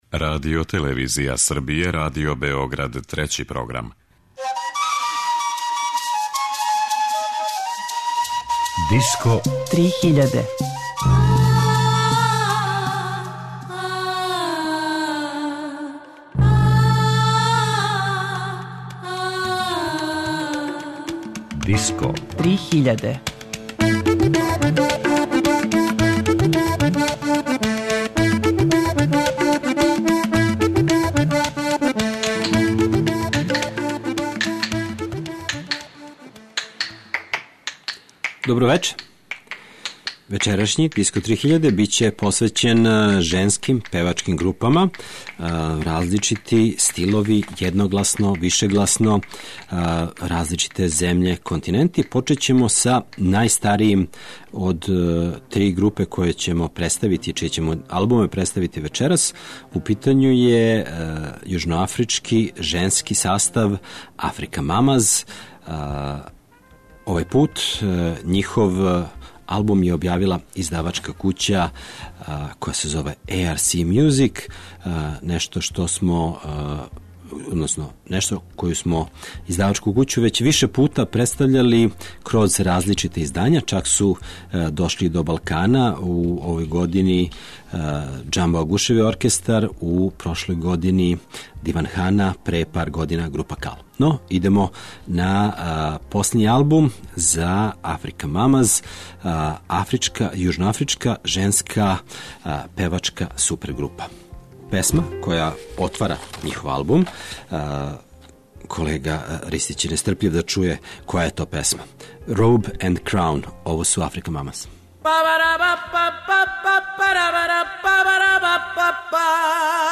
На сцени светске музике женско групно певање је веома популарно и разноврсно. У вечерашњој емисији ћете слушати певање женских група из Шпаније, Јужне Африке и Србије.